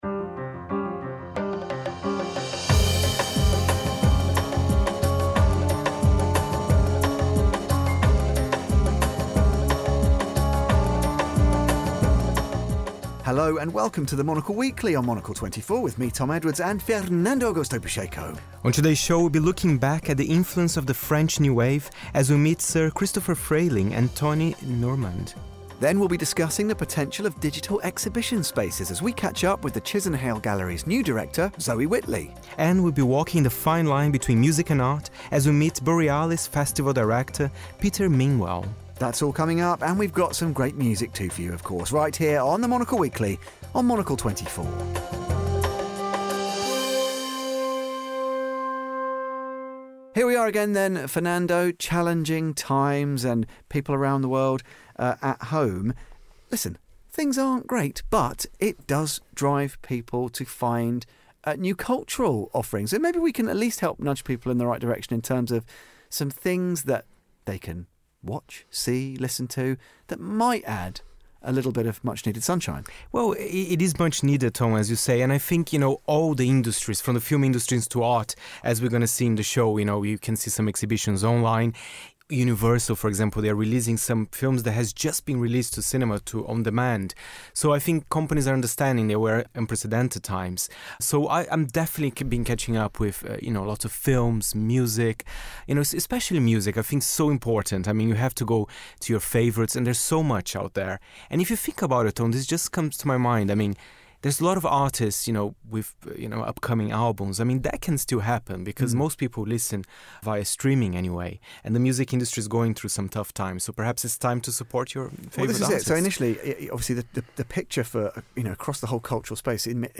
Monocle’s longest-running show delivers insights and interviews weekly.
We speak with the team behind Oscar- and Bafta-nominated film ‘Zootropolis 2’, Hollywood’s biggest-ever animation. We hear from producer Yvett Merino and Jared Bush, director and chief creative officer of Walt Disney Animation Studios.